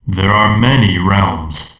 As one of the backwards-speaking people in MKD's Konquest explains...